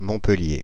1. ^ French: [mɔ̃pəlje, -pɛl-]
Fr-Montpellier.ogg.mp3